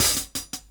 Closed Hats
HATZ_4.wav